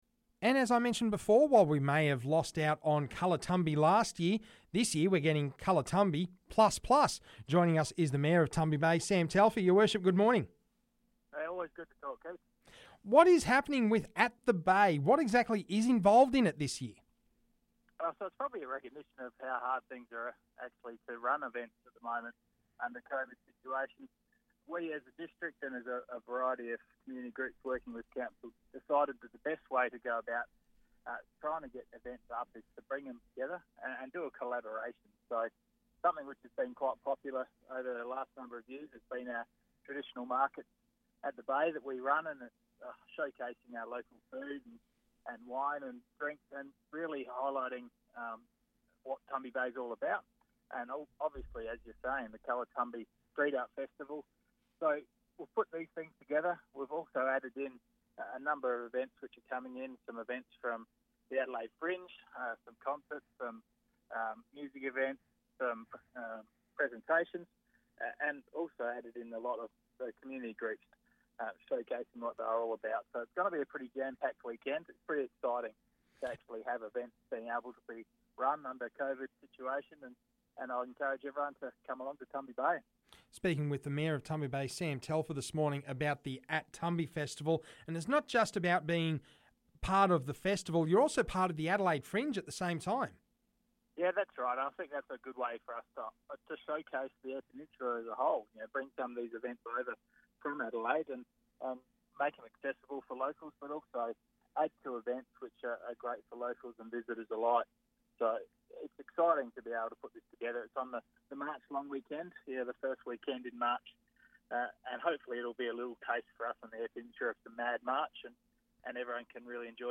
Tumby Bay Mayor Sam Telfer gives us the rundown on the upcoming At The Bay festival, incorporating Colour Tumby.